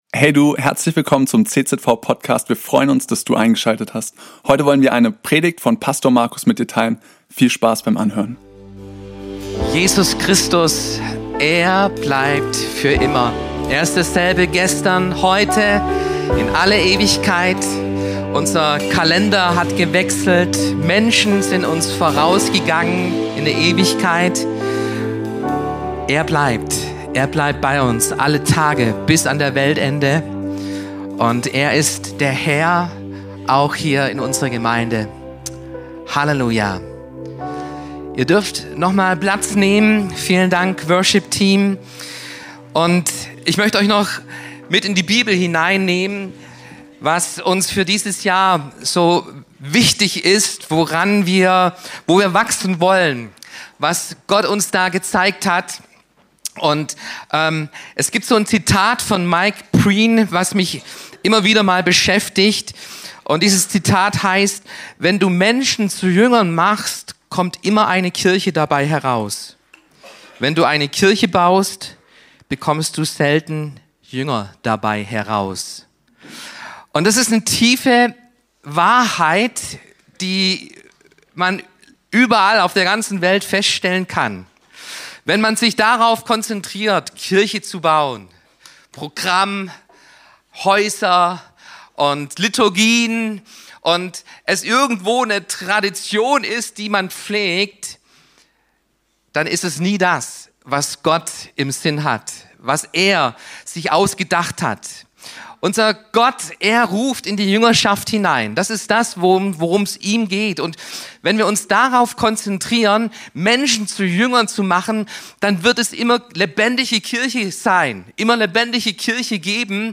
Visionsgottesdienst 2026 ~ CZV Crailsheim Podcast